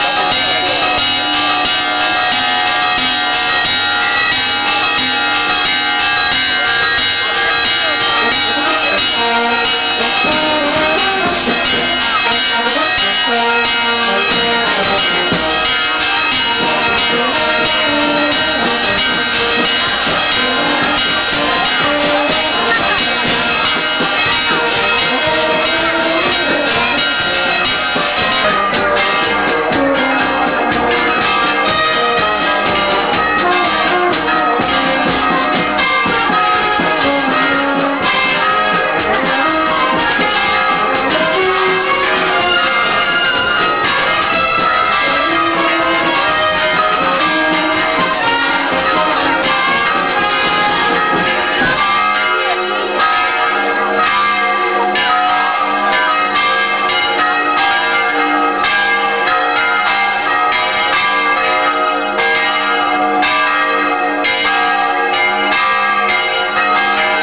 sortie-eglise_jour_test.wav